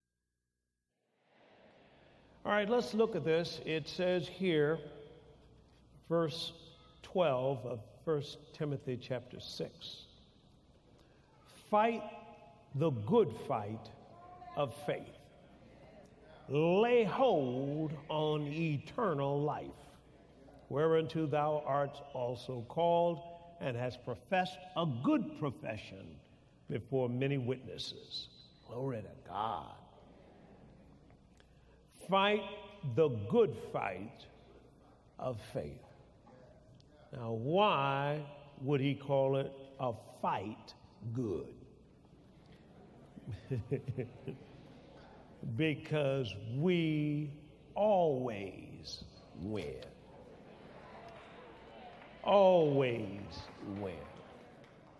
These dynamic anointed speakers shared revelation on the topic of Faith that you do not want to miss.